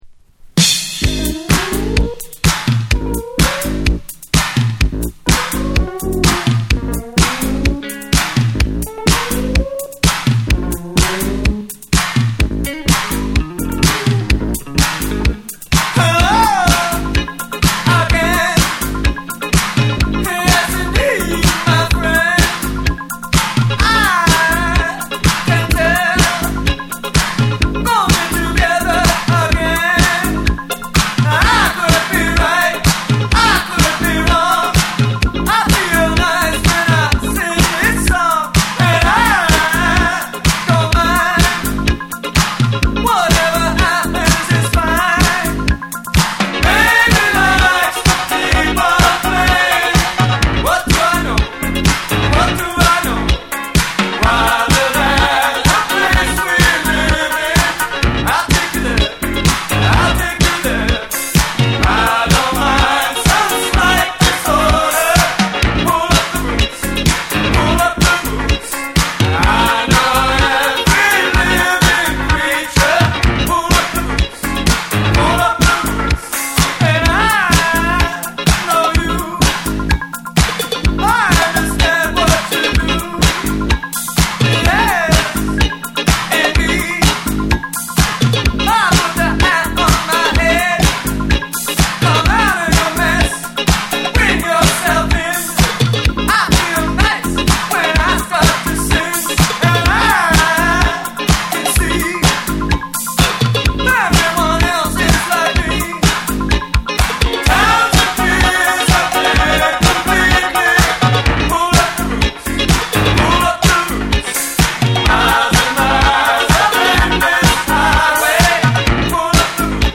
跳ねるようなリズムとユニークなコード進行がクセになる
NEW WAVE & ROCK